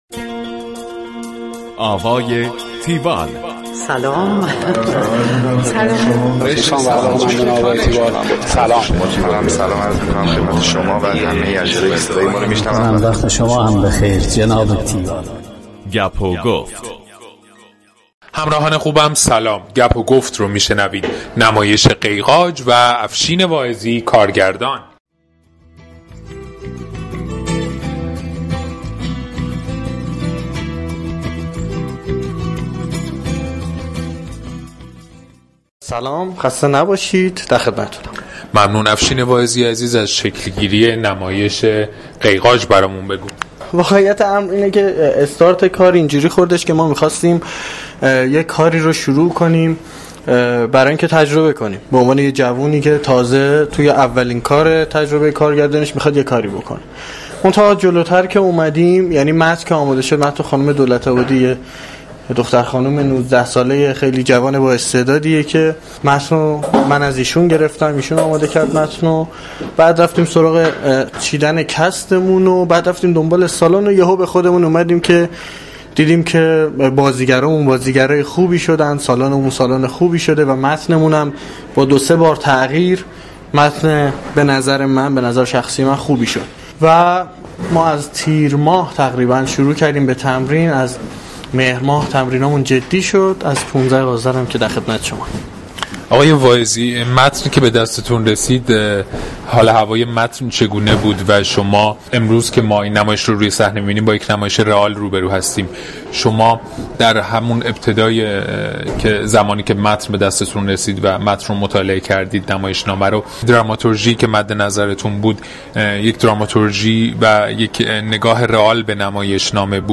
دانلود فایل صوتی گفتگوی تیوال